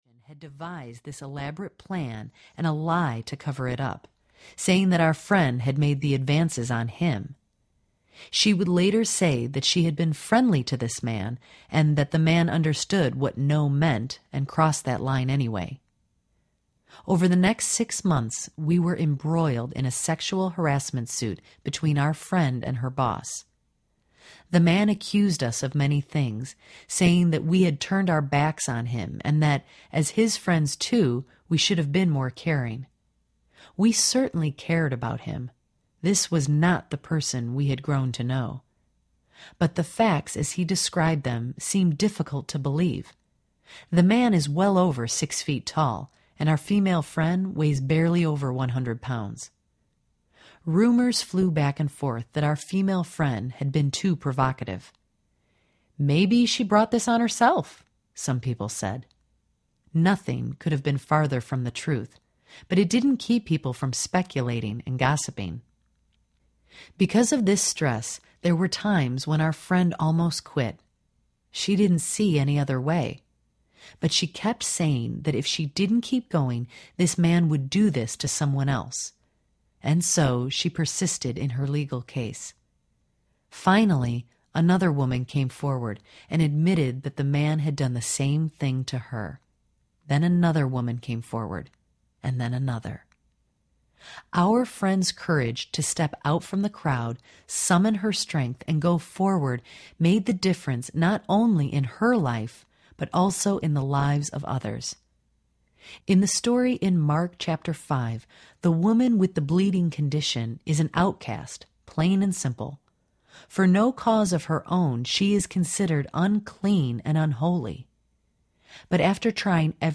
The Cure for the Chronic Life Audiobook
Narrator
7.25 Hrs. – Unabridged